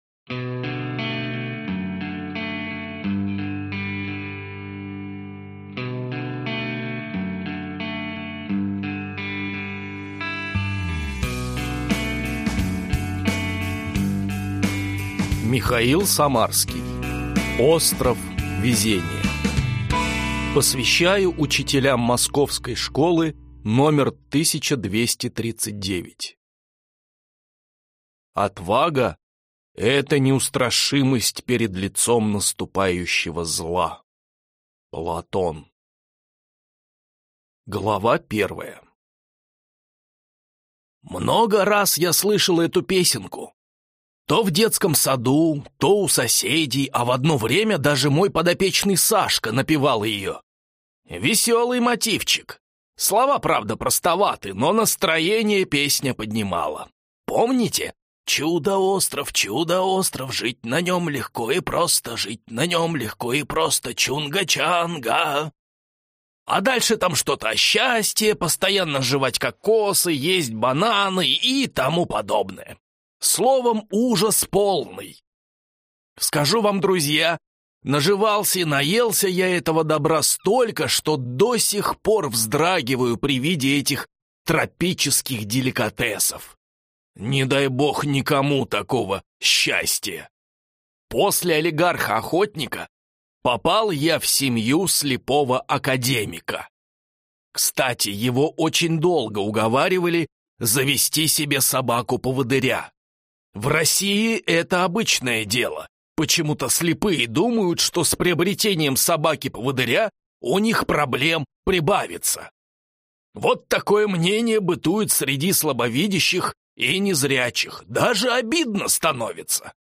Аудиокнига Остров везения | Библиотека аудиокниг